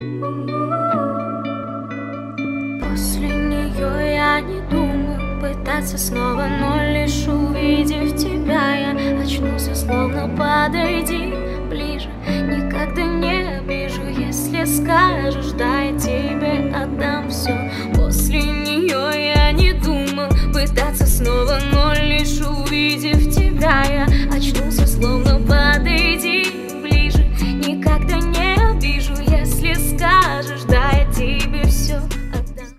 Поп Музыка
кавер
спокойные